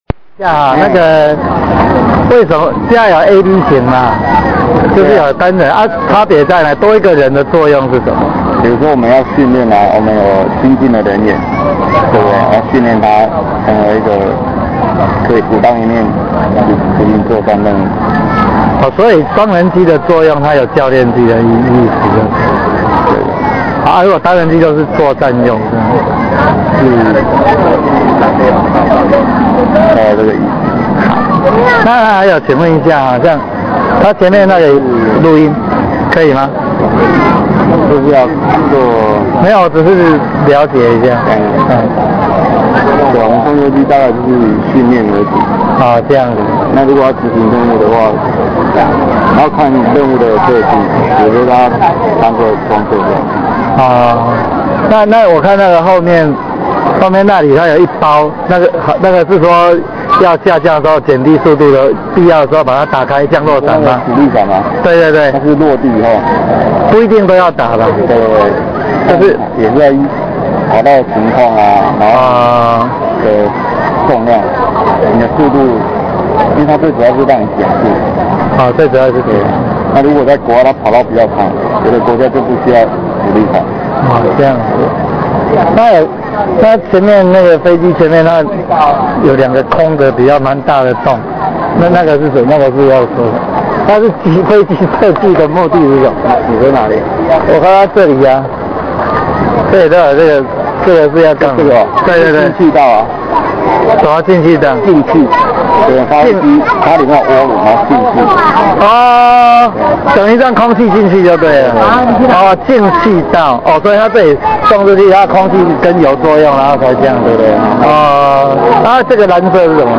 訪談F16戰機